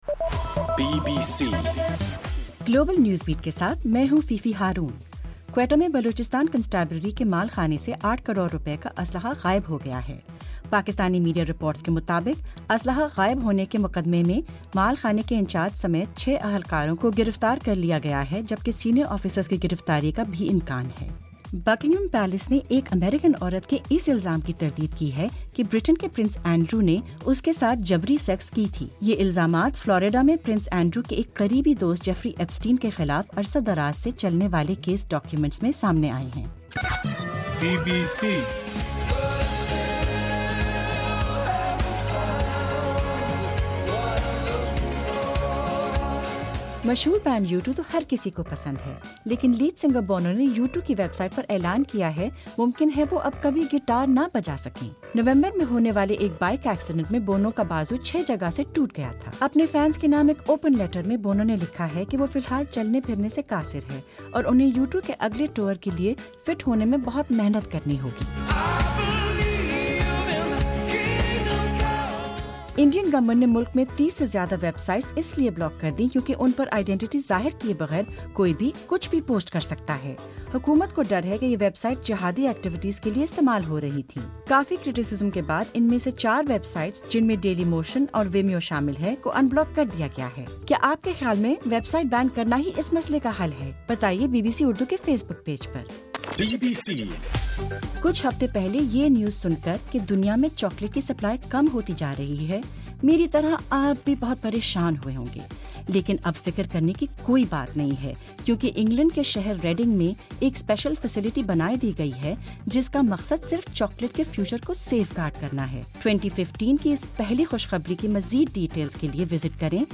جنوری 03: رات 1 بجے کا گلوبل نیوز بیٹ بُلیٹن